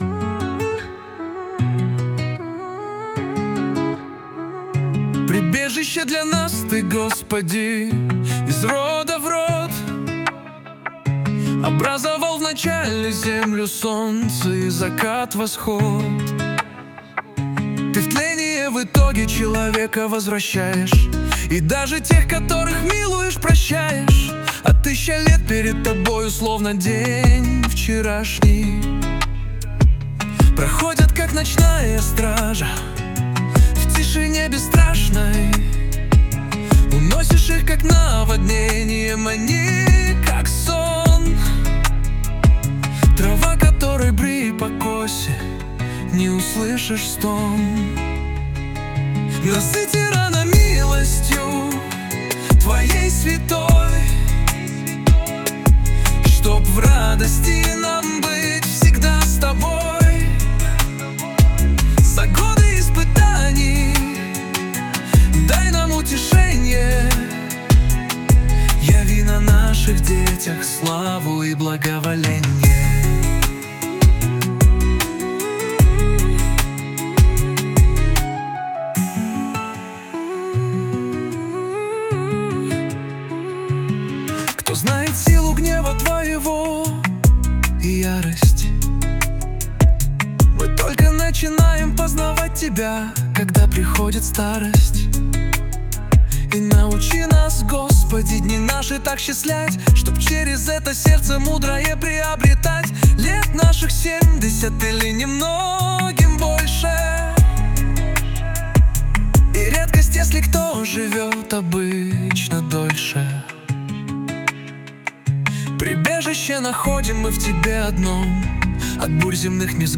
песня ai
218 просмотров 763 прослушивания 88 скачиваний BPM: 129